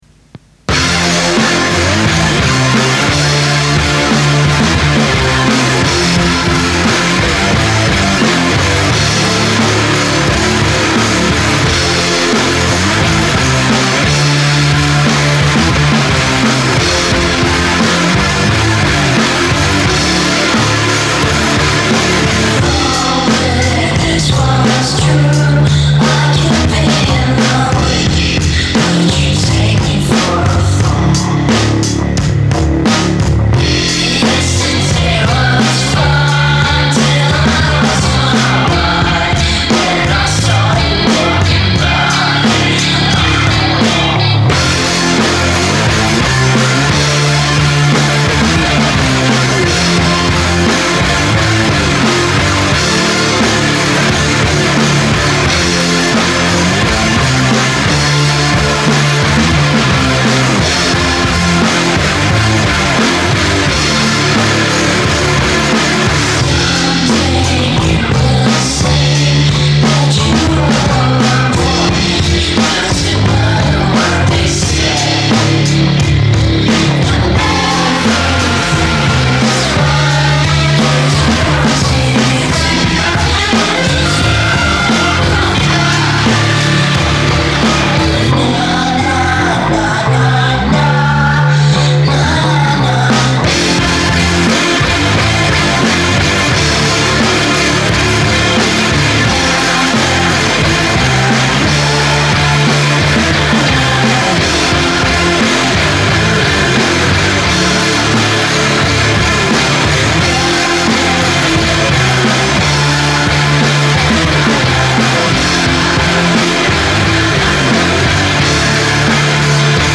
Indie rock Noise rock Lo-fi